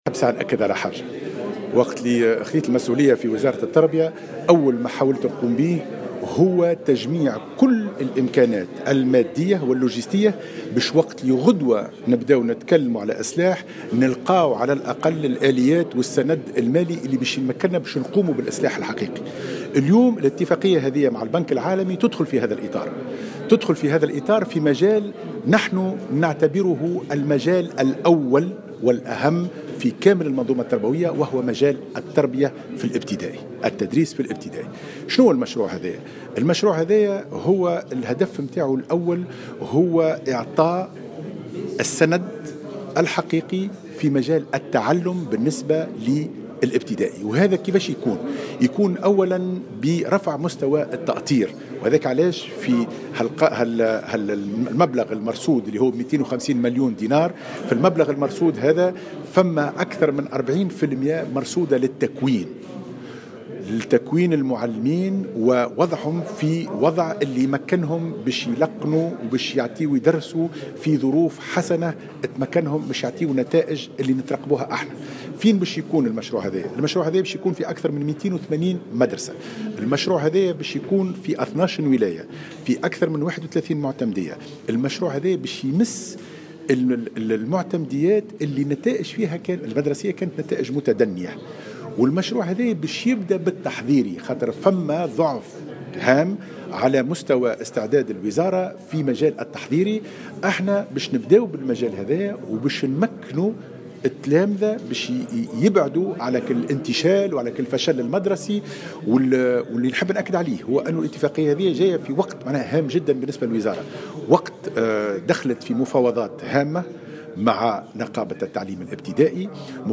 أكد وزير التربية حاتم بن سالم في تصريح لمراسل الجوهرة "اف ام" اليوم الخميس على هامش توقيع اتفاقيتي تمويل مع البنك العالمي بقيمة 610 مليون دينار لفائدة قطاعي التربية والفلاحة أهمية القرض في هذه الفترة بالذات مع تقدم المفاوضات مع نقابة التعليم الأساسي.